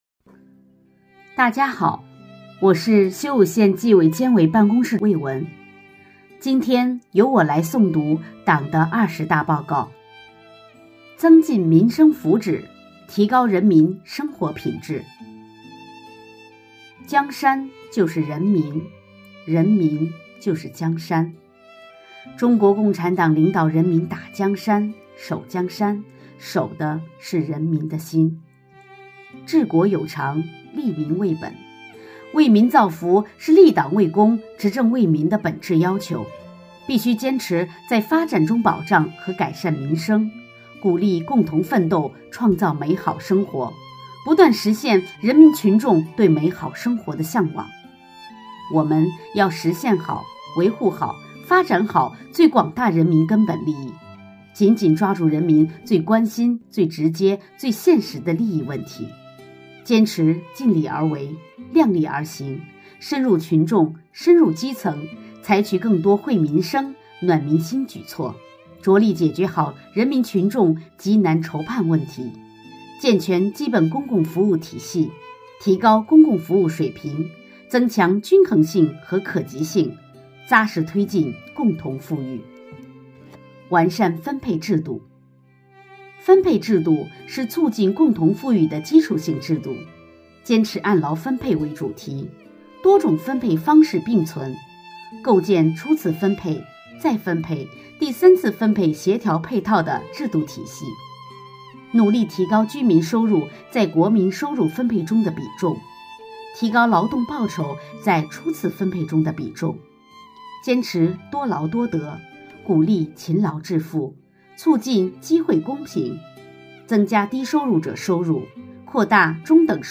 诵读内容